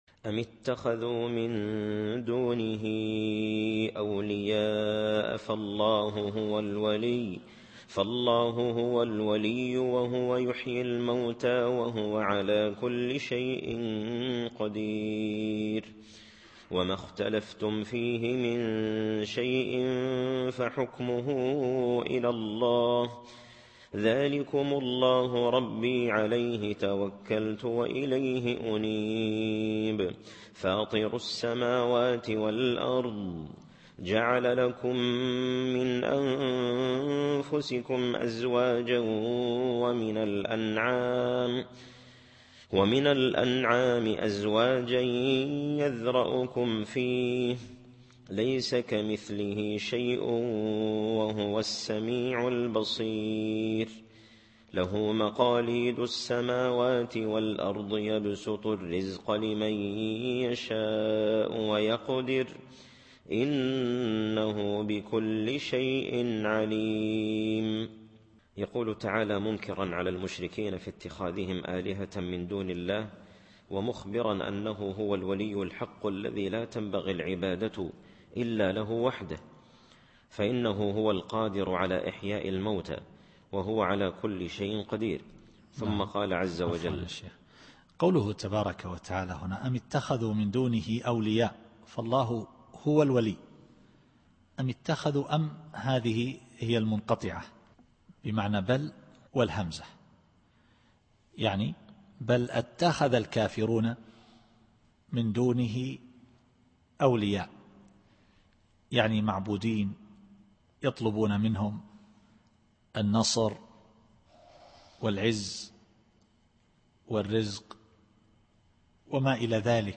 التفسير الصوتي [الشورى / 10]